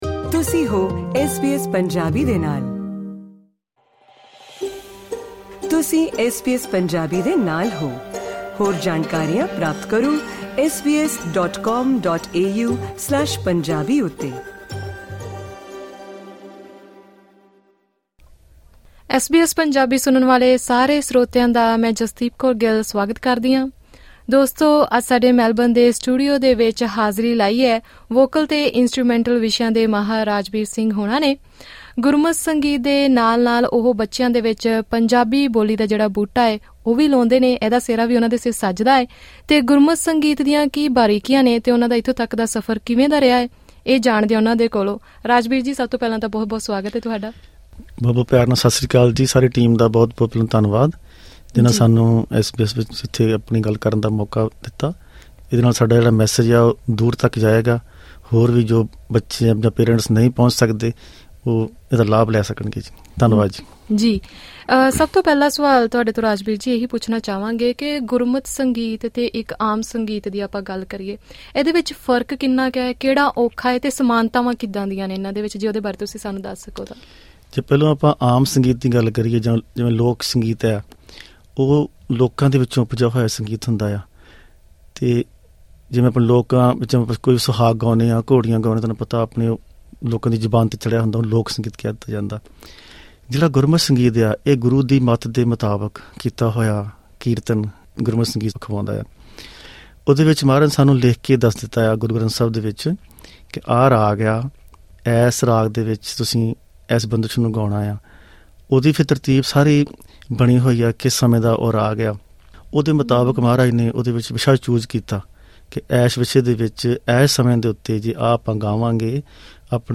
ਐਸ ਬੀ ਐਸ ਪੰਜਾਬੀ ਨਾਲ ਗੱਲਬਾਤ ਕਰਦਿਆਂ ਉਹਨਾਂ ਦੱਸਿਆ ਕਿ 31 ਸ਼ੁੱਧ ਰਾਗ ਹਨ ਅਤੇ 31 ਰਾਗ ਪ੍ਰਕਾਰਾਂ ਹਨ। ਉਹਨਾਂ ਦੱਸਿਆ ਕਿ ਬਹੁਤ ਸਾਰੇ ਰਾਗ ਰੁੱਤਾਂ ਦੇ ਹਿਸਾਬ ਨਾਲ ਗਾਏ ਜਾਂਦੇ ਹਨ ਜਿਵੇਂ ਕਿ ਬਸੰਤ ਰਾਗ ਅਤੇ ਮਲਹਾਰ ਰਾਗ।